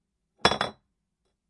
空牛奶瓶被打翻
描述：空牛奶瓶在混凝土上被撞倒，但没有破裂
标签： 空奶瓶 玻璃
声道立体声